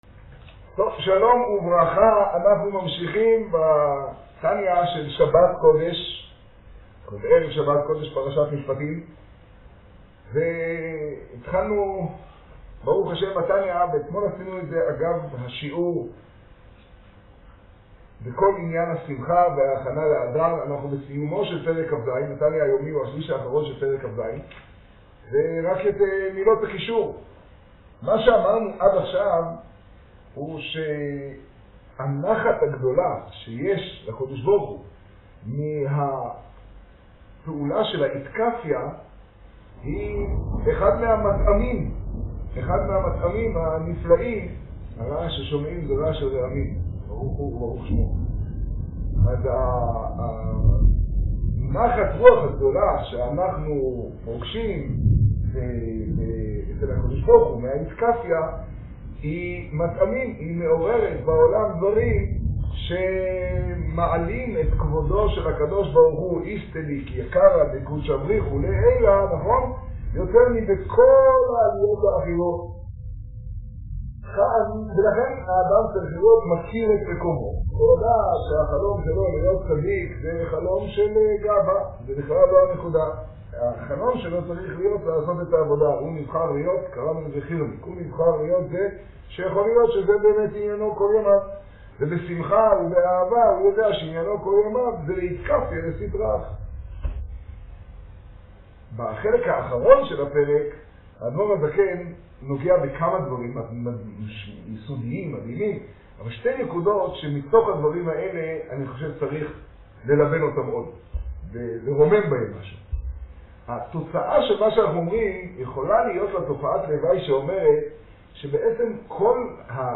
השיעור במגדל, כה שבט תשעה.